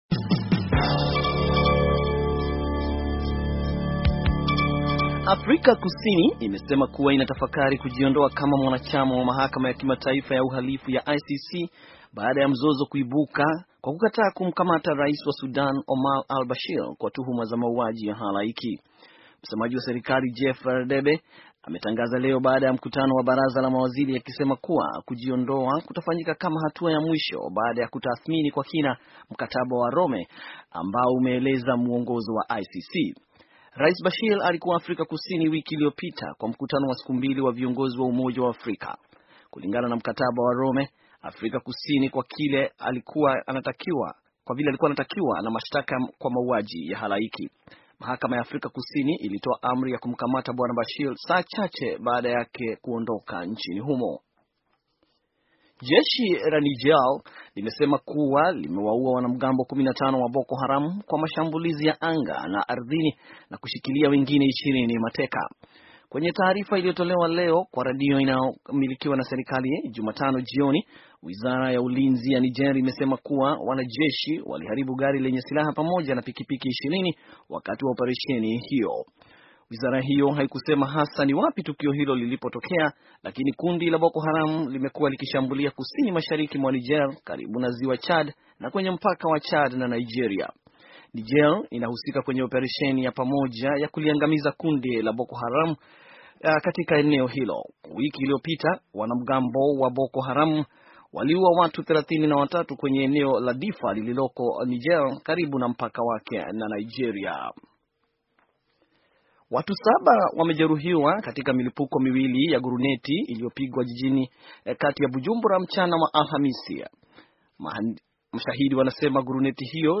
Taarifa ya habari - 6:23